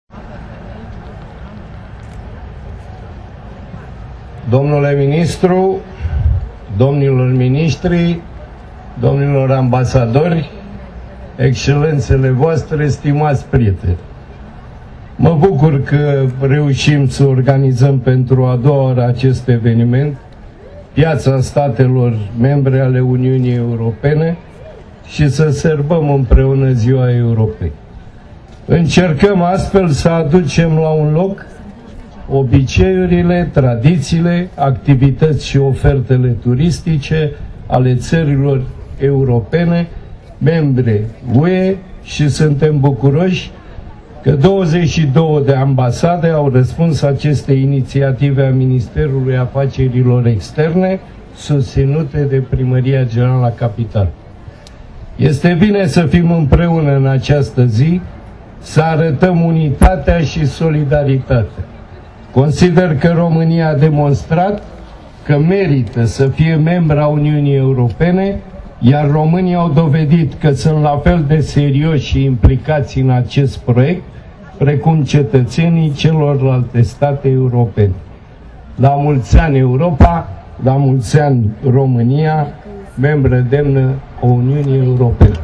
Primarul General Sorin Oprescu a sărbătorit Ziua Europei, împreună cu ministrul afacerilor externe Bogdan Aurescu, cu șefa Reprezentanței Comisiei Europene la București Angela Filote și cu ambasadori ai statelor membre UE la București, in Piața Statelor Uniunii Europene.
Primarul General al Municipiului Bucuresti Sorin Oprescu:
Oprescu-discurs1.mp3